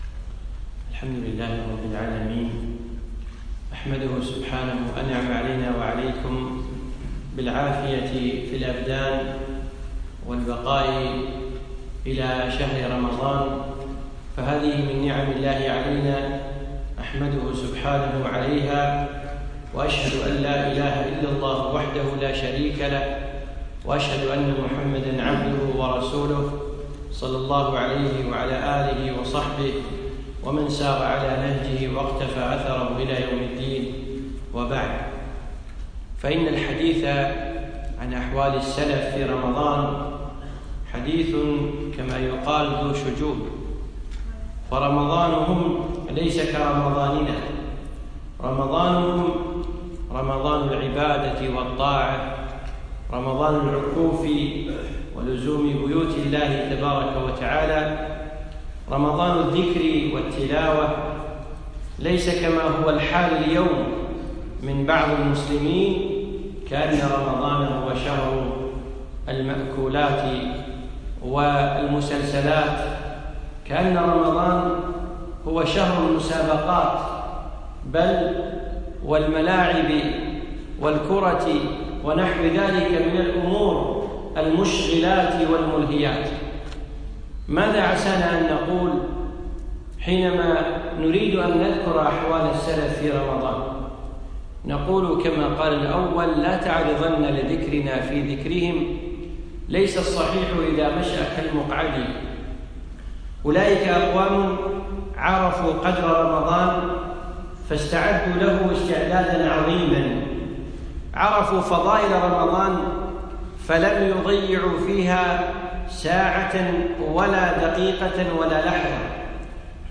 يوم السبت 28 شعبان 1437هـ الموافق 4 6 2016م في مسجد عايض المطيري الفردوس